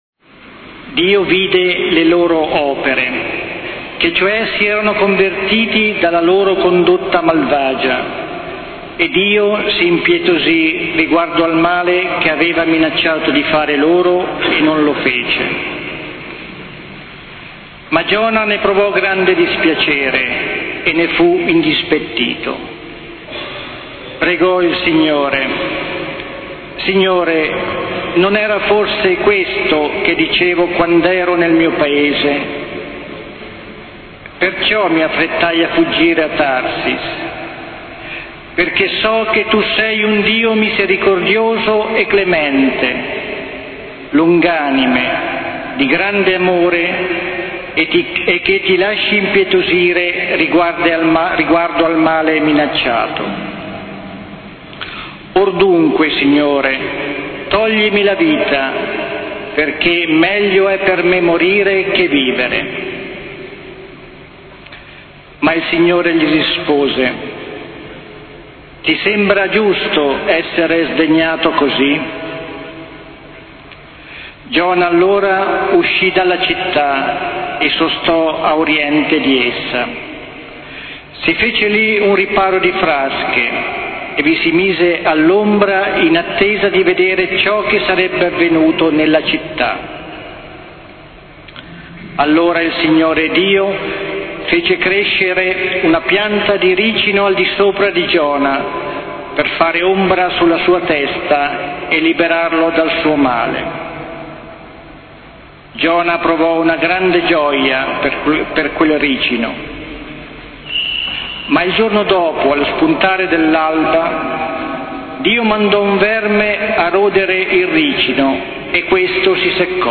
Quinto Quaresimale, San Pio X di Fano
Autore: ARMANDO TRASARTI Vescovo di Fano Fossombrone Cagli Pergola
Quinto-Quaresimale-Parrocchia-San-Pio-X-in-Fano-7-aprile-2014.mp3